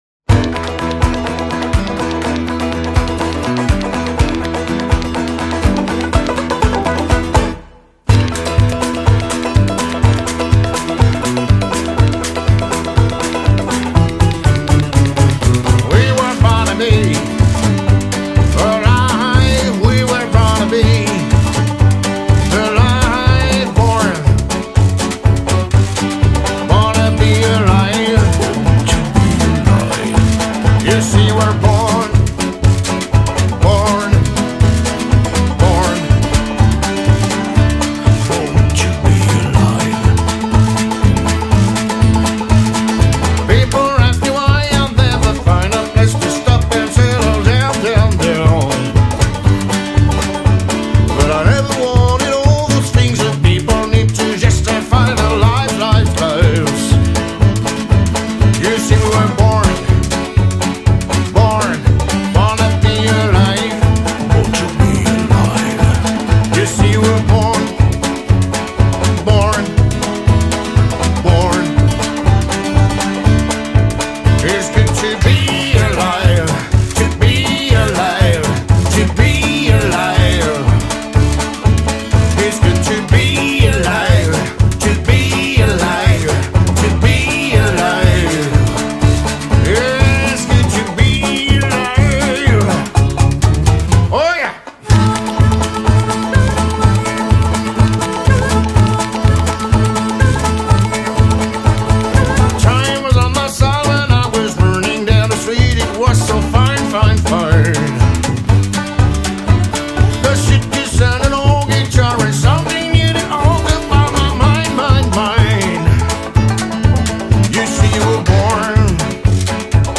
avec une saveur folk rock et swing !